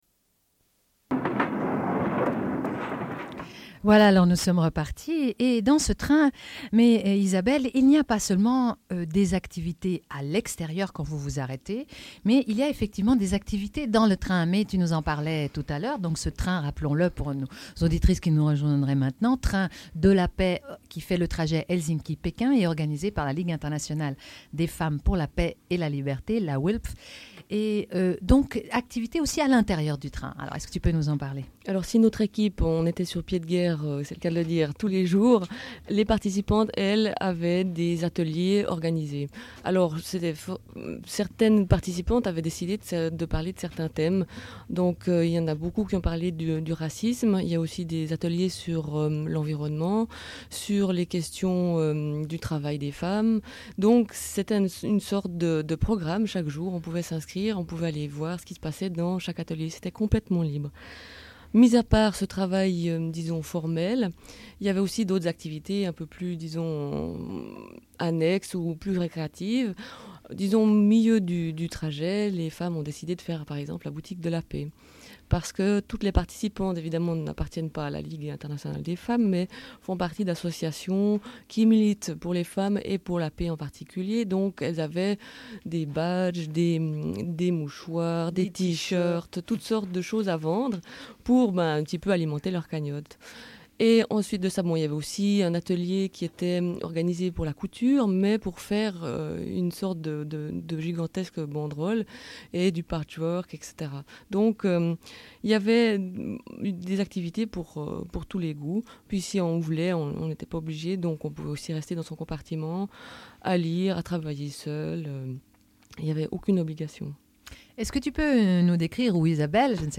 Elles diffusent des entretiens réalisés dans le train. À l'occasion de la Conférence internationale des femmes organisée par l'ONU ainsi que le Forum des femmes qui a lieu en parallèle.